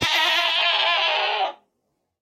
Minecraft Version Minecraft Version 1.21.5 Latest Release | Latest Snapshot 1.21.5 / assets / minecraft / sounds / mob / goat / screaming_death3.ogg Compare With Compare With Latest Release | Latest Snapshot
screaming_death3.ogg